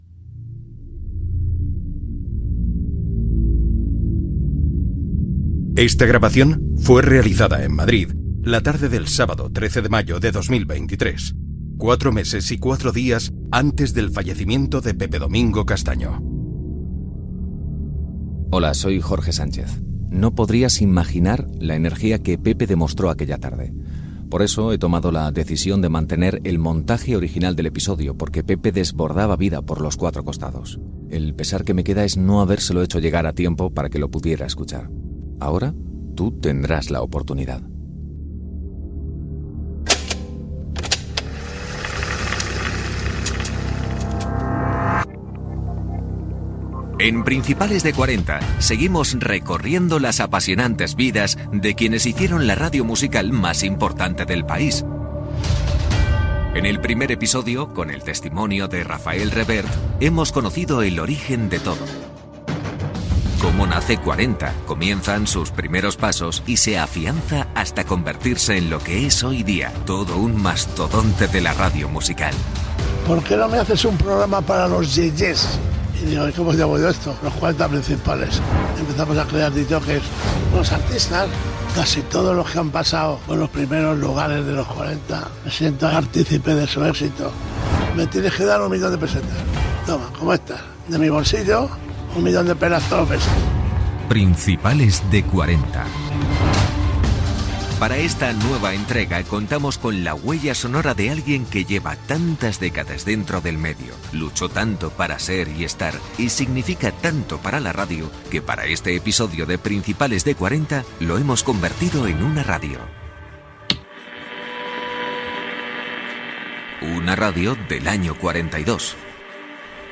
Careta del programa. Espai dedicat a Pepe Domingo Castaño, la seva biografia personal, els seus records i la seva activitat professional. Paraules finals d'Iñaki Gabilondo i Paco González.